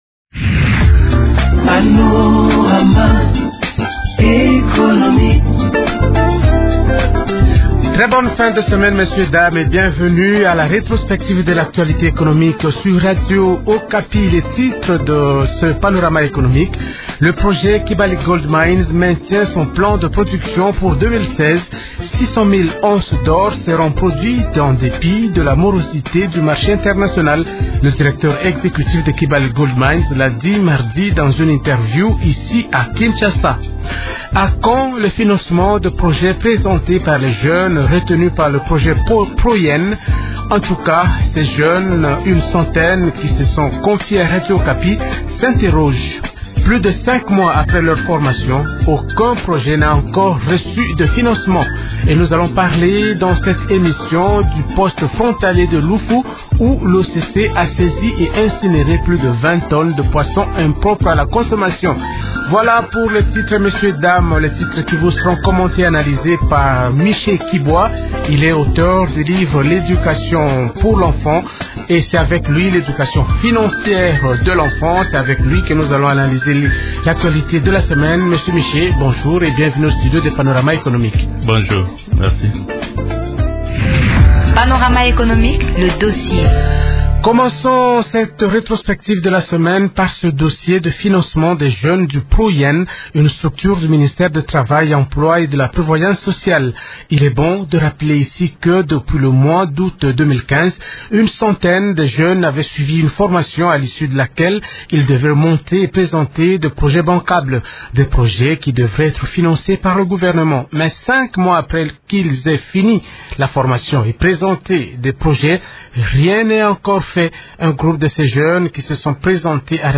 Ils ont exprimé leur besoin dans un entretien accordé, mercredi 20 janvier, à Radio Okapi.